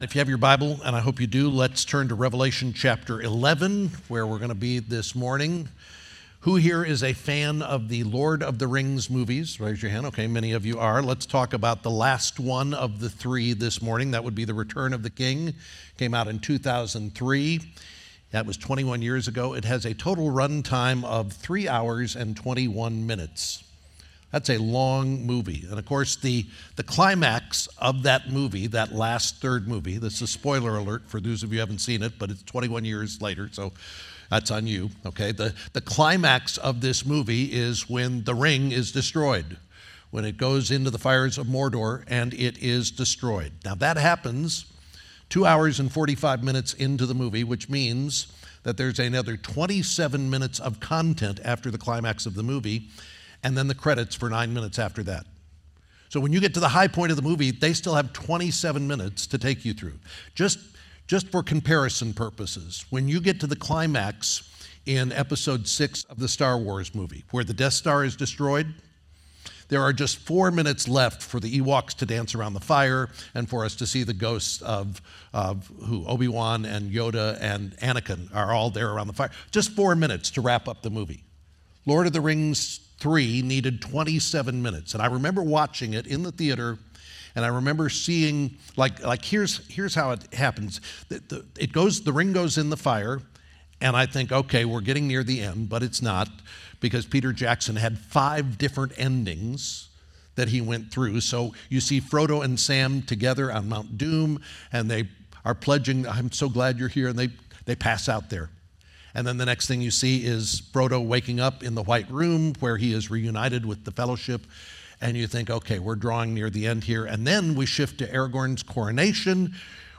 2024 Revelation Revelation 11:15-19 The next sermon in our series through the book of Revelation focusing on the King Jesus who rules over all things and will reward his people in his timing.